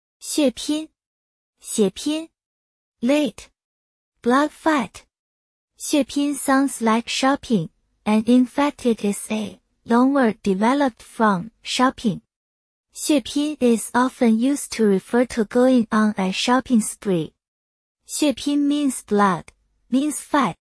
xiě pīn